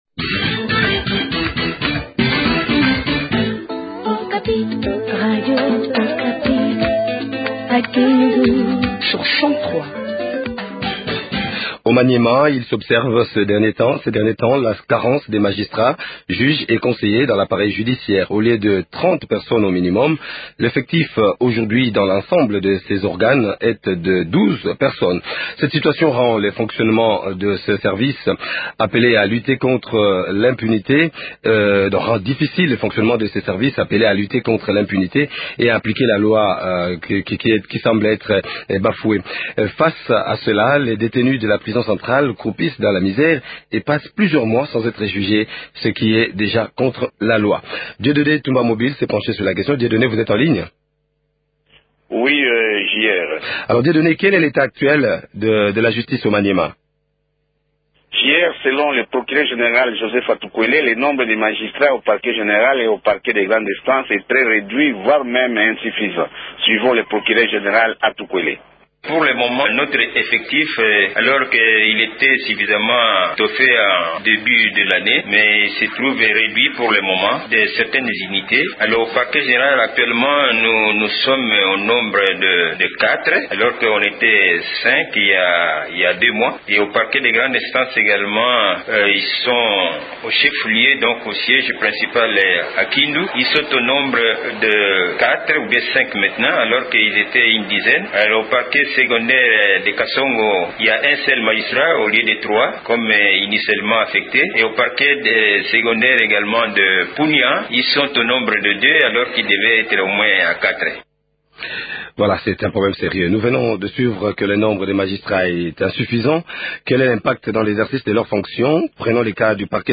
debattent de la question avec Alphonse Igwa Ngozi ,ministre provincial a la justice